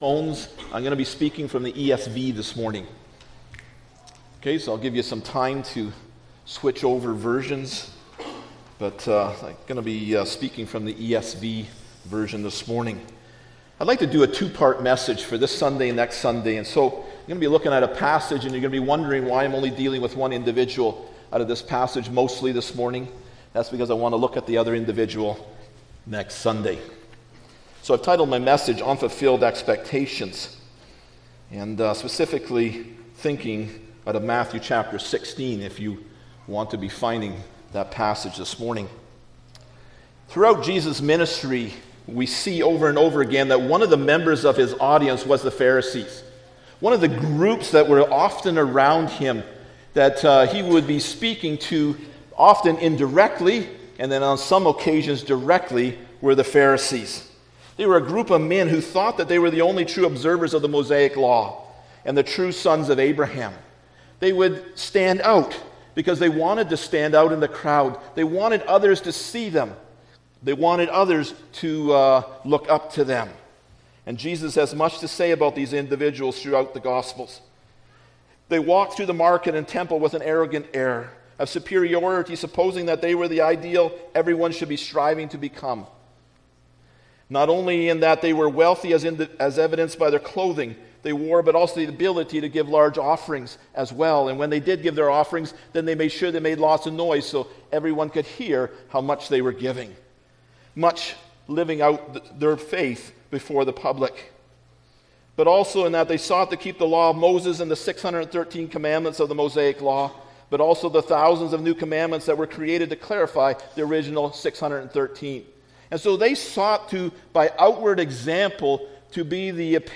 Luke 16:19-31 Service Type: Sunday Morning Bible Text